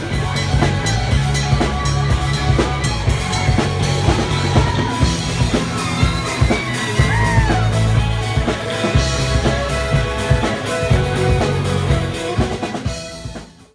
(audio captured from youtube video montage)